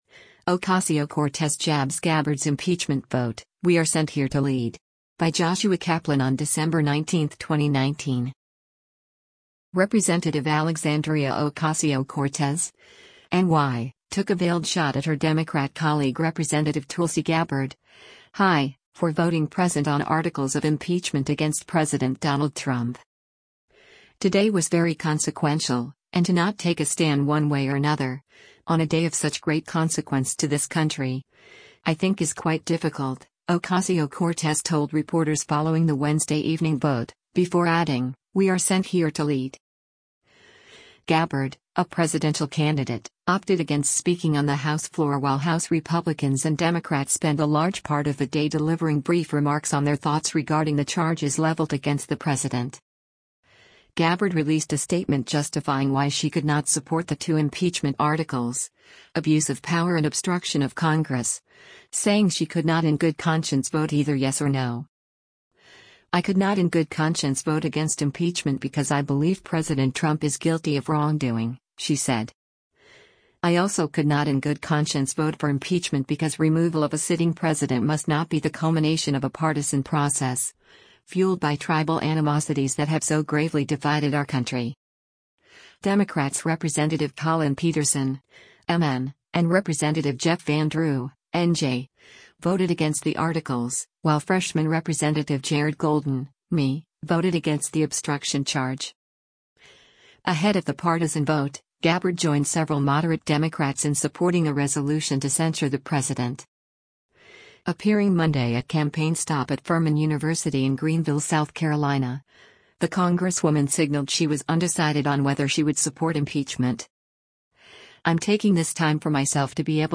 “Today was very consequential, and to not take a stand one way or another, on a day of such great consequence to this country, I think is quite difficult,” Ocasio-Cortez told reporters following the Wednesday evening vote, before adding, “We are sent here to lead.”